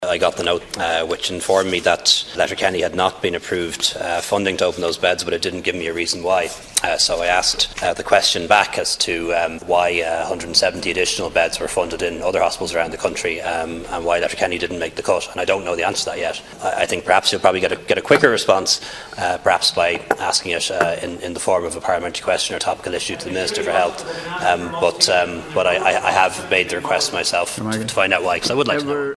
Leo Varadkar was quizzed on the issue by Donegal Deputy Charlie McConalogue in the Dail last evening.
Responding, the Taoiseach suggested that a more speedily response may be obtained if the question was asked through parliamentary question to the Health Minister: